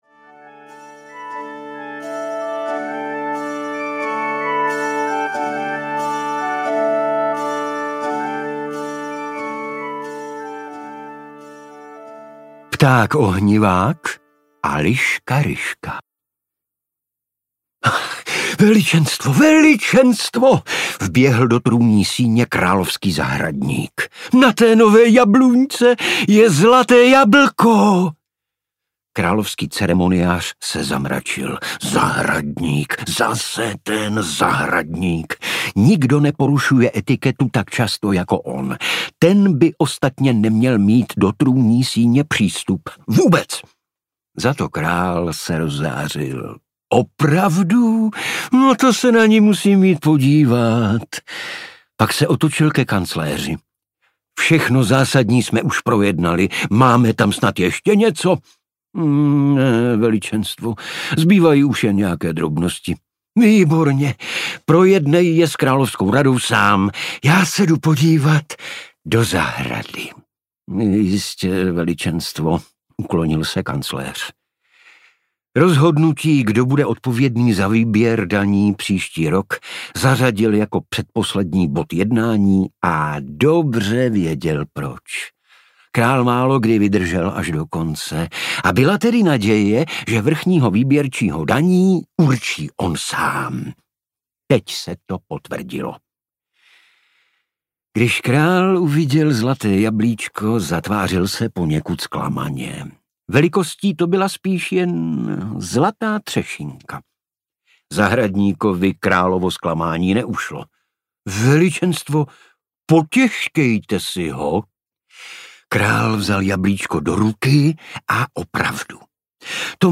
Nevykládej mi pohádky audiokniha
Ukázka z knihy
• InterpretIgor Bareš